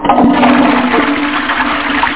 TOILET.mp3